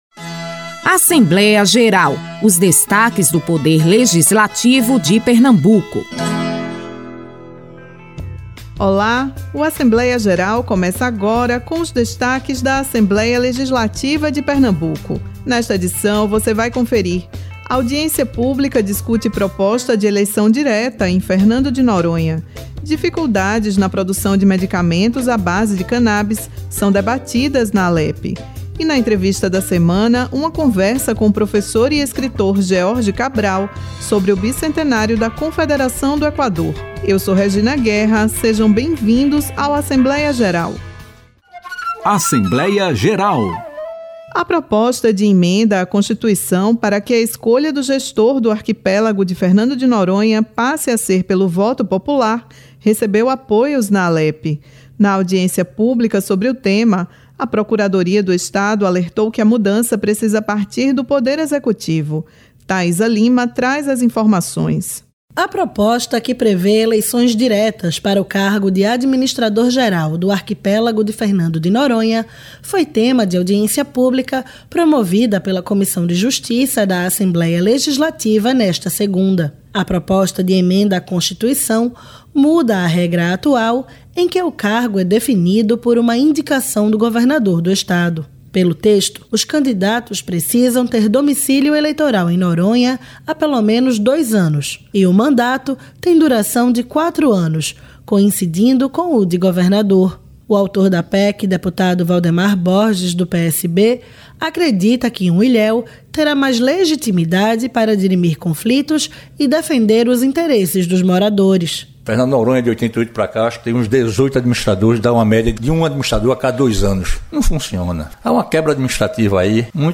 Na edição desta semana do programa Assembleia Geral, você vai conferir os debates realizados pela Assembleia Legislativa de Pernambuco (Alepe) sobre a proposta de eleição direta em Fernando de Noronha e o acesso a medicamentos à base de Cannabis.